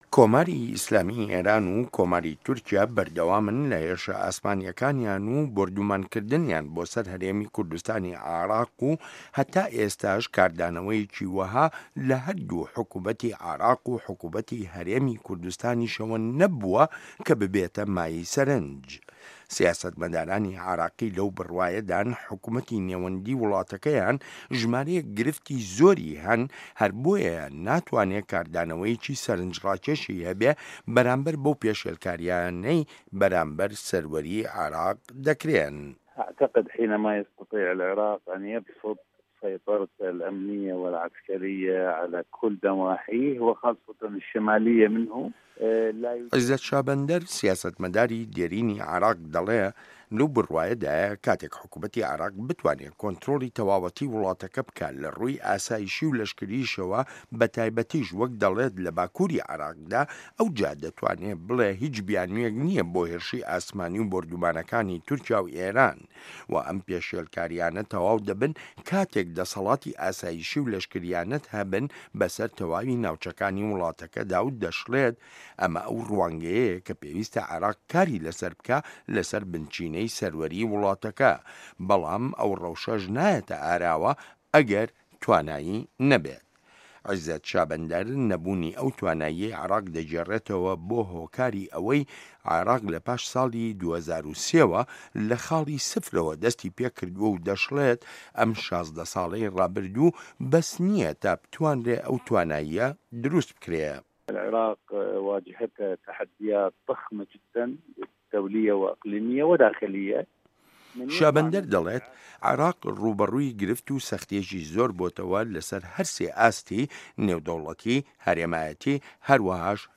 ڕاپۆرتی ئێران و تورکیا و پێشێلکاری سەروەریی عێراق